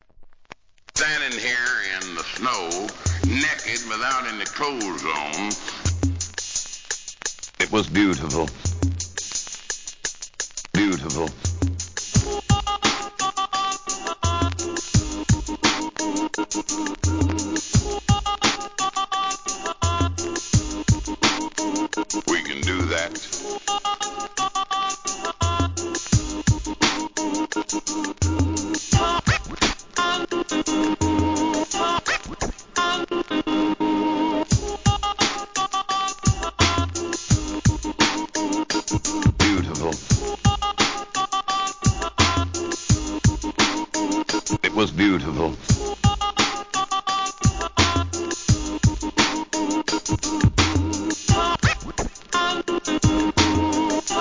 HIP HOP/R&B
2002年　ブレイクビーツ US